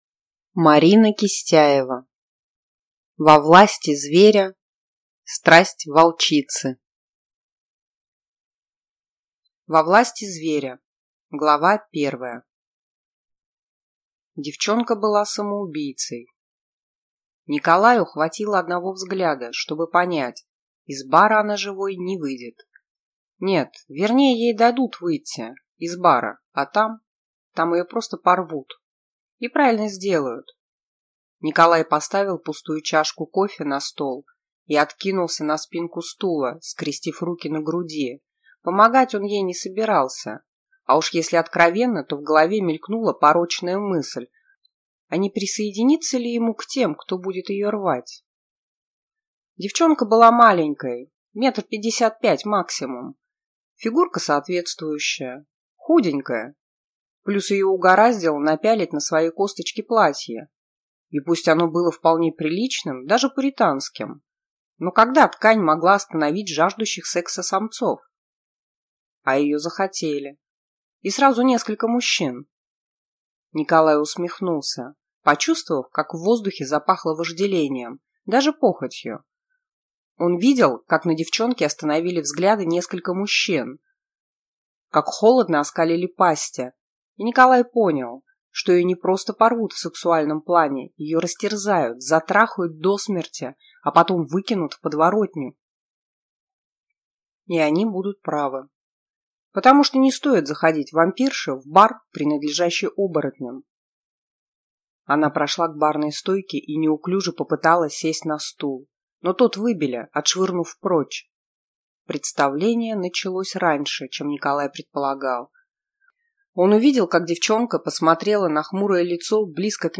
Аудиокнига Во власти зверя. Страсть волчицы (сборник) | Библиотека аудиокниг